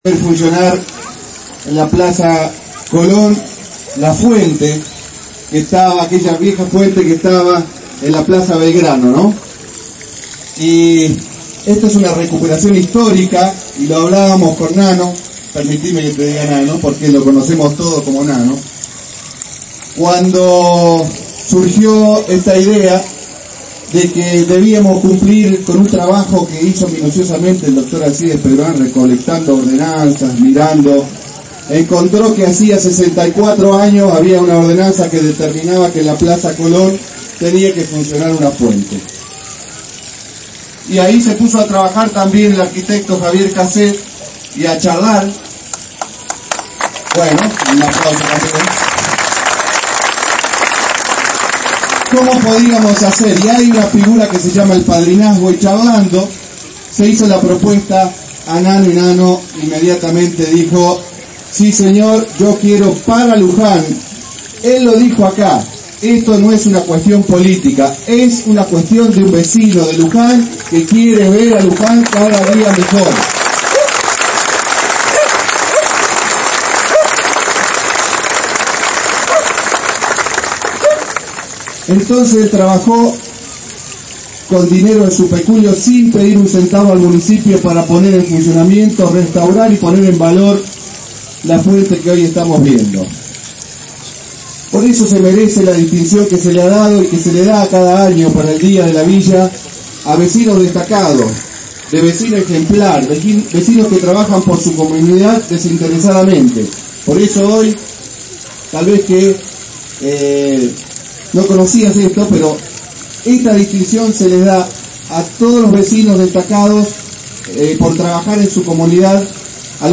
Con una gran cantidad de público se inauguró este miércoles por la tarde en un acto encabezado por el Intendente Oscar Luciani la Fuente de los Deseos en la Plaza Colón, realizada por la empresa Aguas Cristalinas bajo el régimen de padrinazgo legalmente autorizado.
AUDIO: Intendente Oscar Luciani
luciani_fuente.mp3